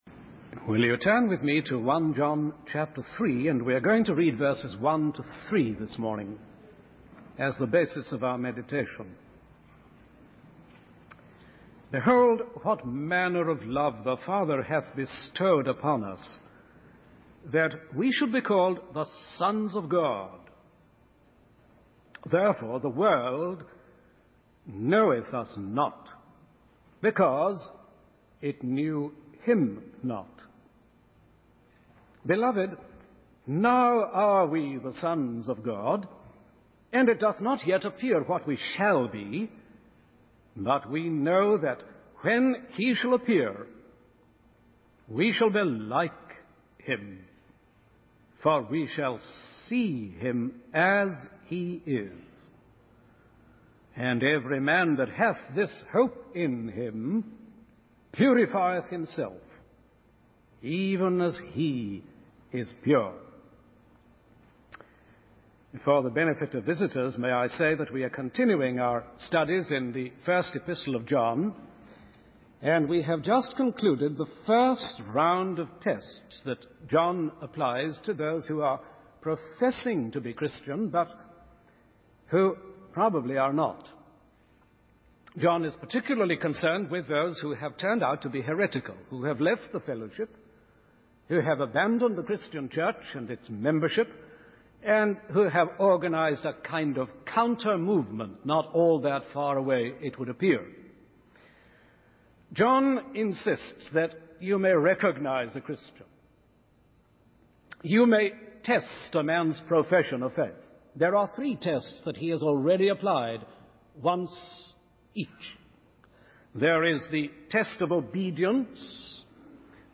In this sermon, the speaker emphasizes the unique quality of God's love. He describes how the apostle John, even in his old age, is still amazed by the love of God for sinners.